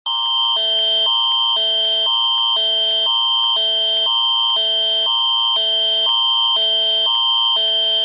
一旦有上述事件发生，系统会按照地区强制性地在你的手机发出尖锐的警报，并出现双语文字提醒。
BC省这两天正在进行警报系统测试，全省的电视和广播都在播放这种警报声音。